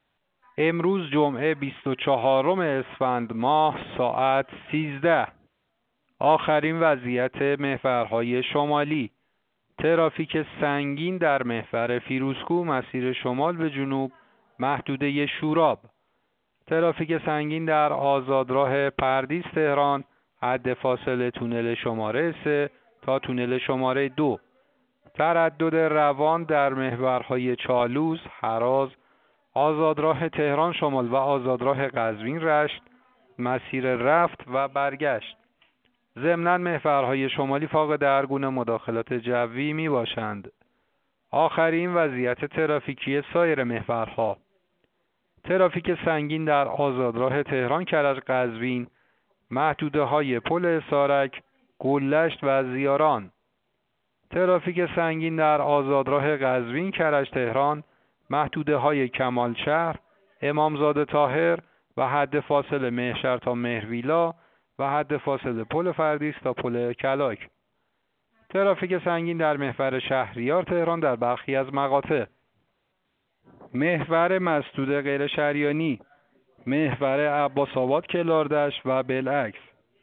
گزارش رادیو اینترنتی از آخرین وضعیت ترافیکی جاده‌ها ساعت ۱۳ بیست و چهارم اسفند؛